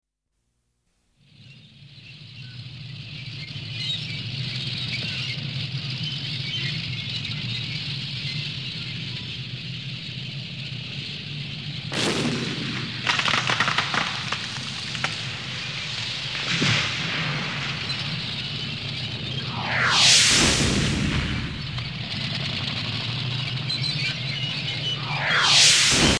دانلود آهنگ پرندگان از افکت صوتی طبیعت و محیط
دانلود صدای پرندگان از ساعد نیوز با لینک مستقیم و کیفیت بالا
جلوه های صوتی